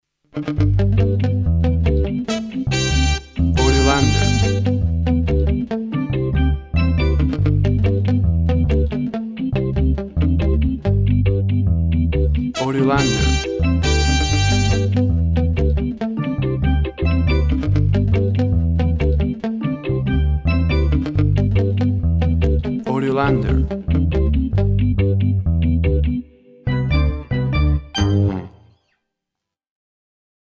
WAV Sample Rate 16-Bit Stereo, 44.1 kHz
Tempo (BPM) 70